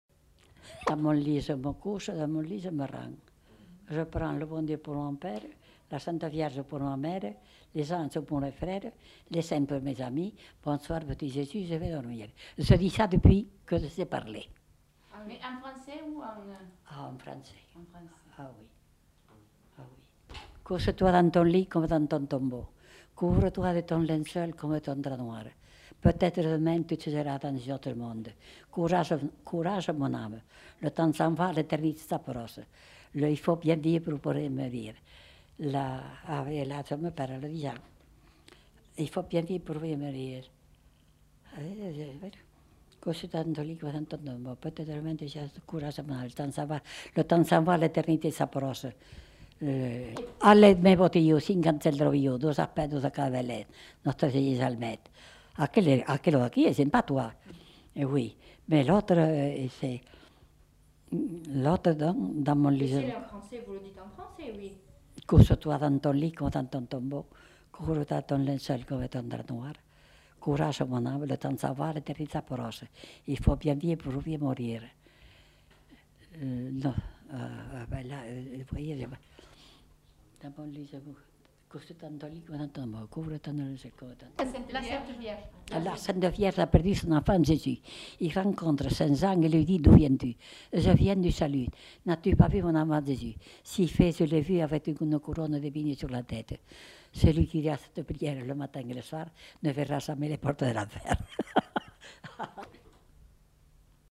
Aire culturelle : Haut-Agenais
Lieu : Condezaygues
Genre : chant
Effectif : 1
Type de voix : voix de femme
Production du son : récité
Classification : prière